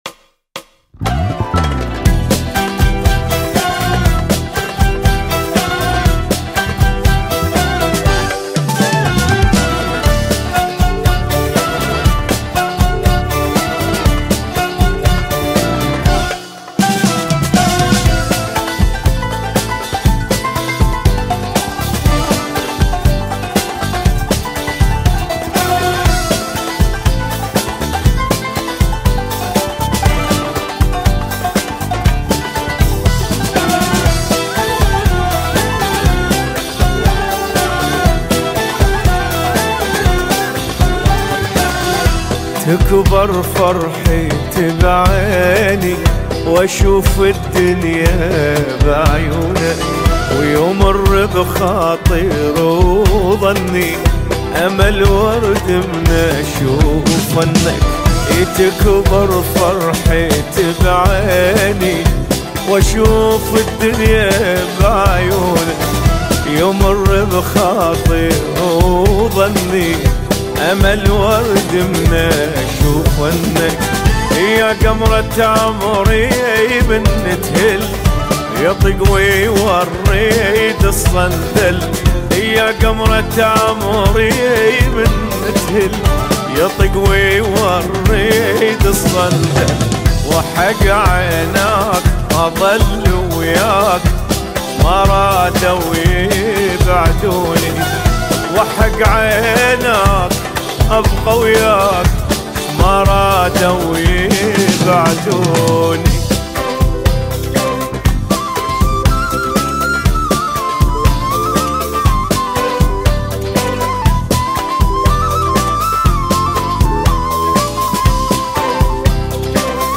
اغانى عراقيه